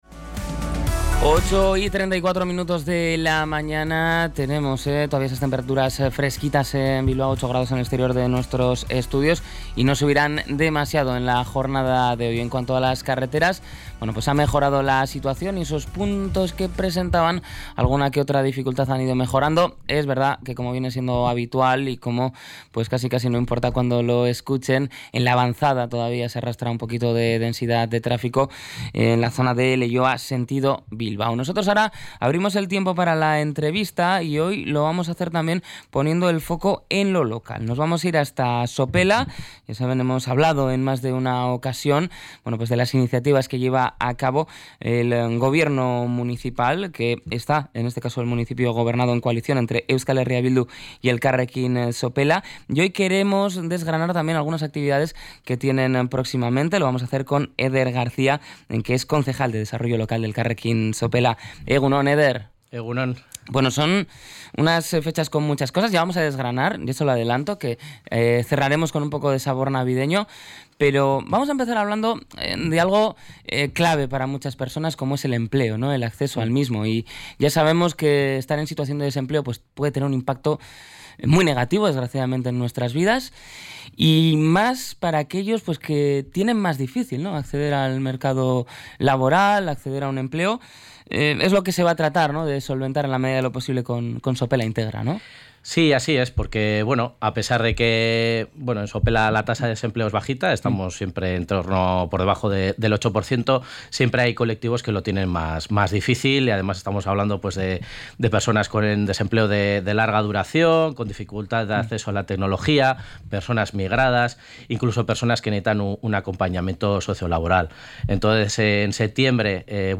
El Concejal de Desarrollo Local, Eder García, detalla el programa Sopela Integra y la campaña de bonos para fomentar el comercio